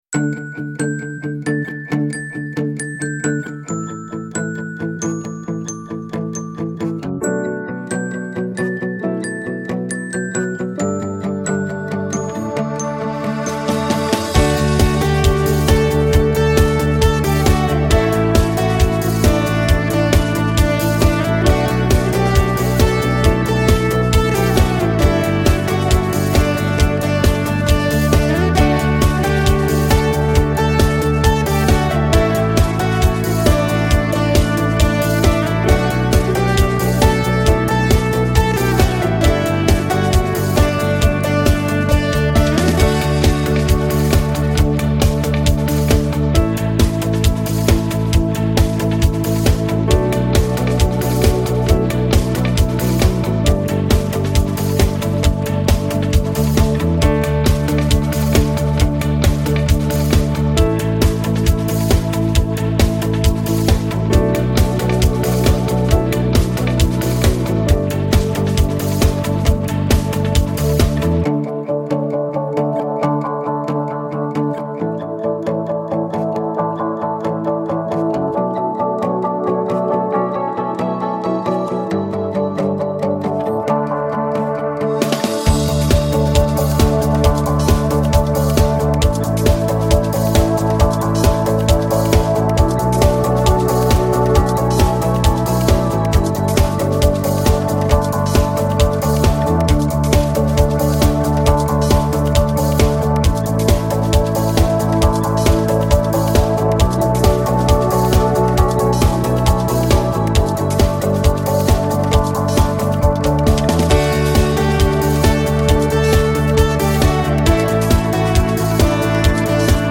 - bgm.mp3 - background music